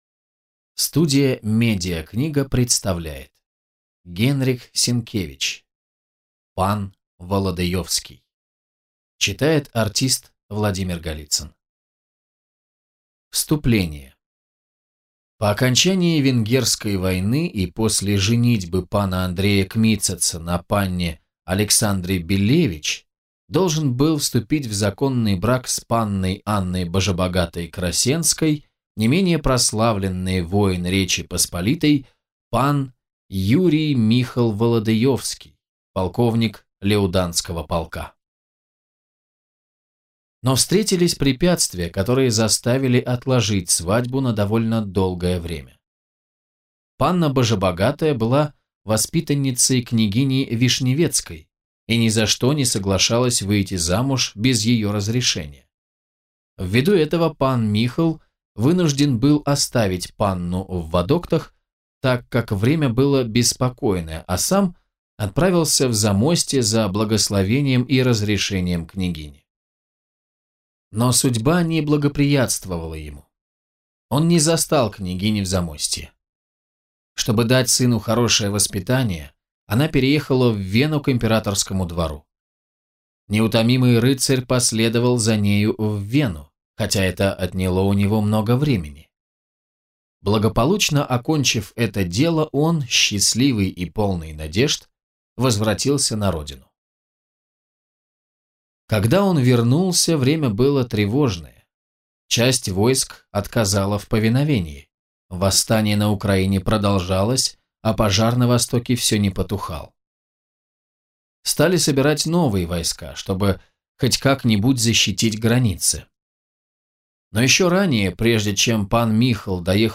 Аудиокнига Пан Володыёвский | Библиотека аудиокниг